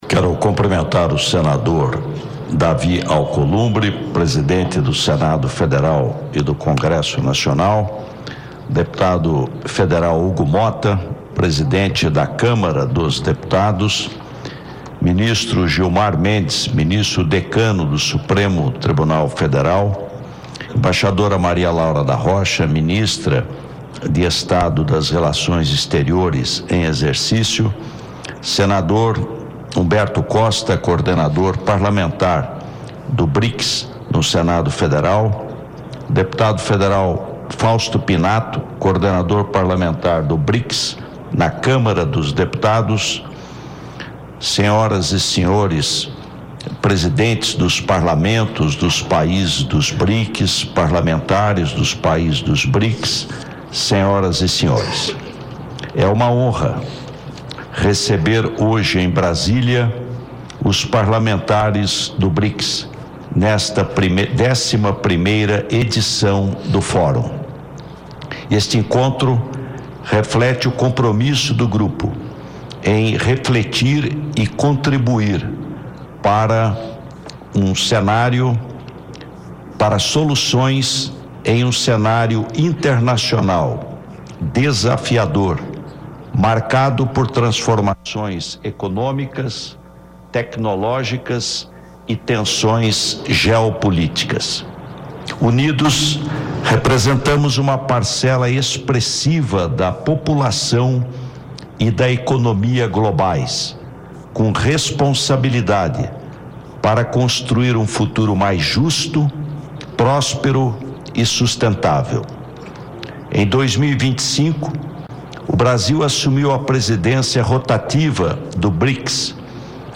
Ouça a íntegra do discurso do presidente da República em exercício, Geraldo Alckmin, na abertura de 11º Fórum Parlamentar do Brics